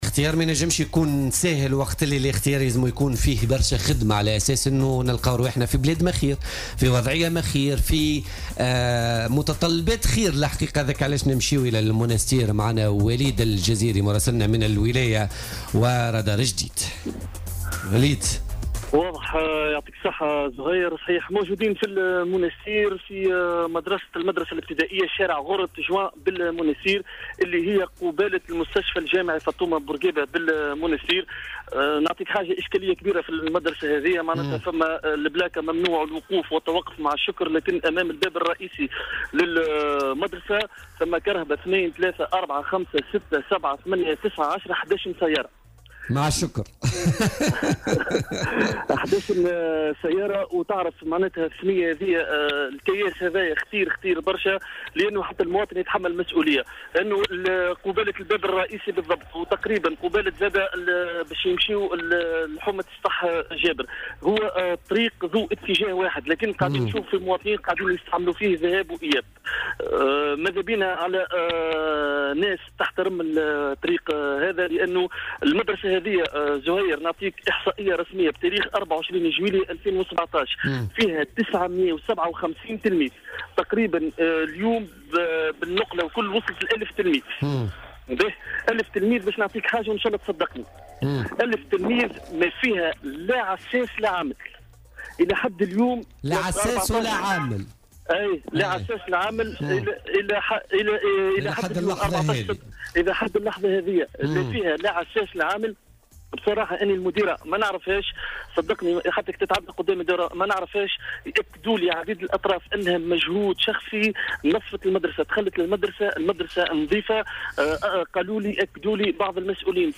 في مداخلة له اليوم في برنامج "بوليتيكا"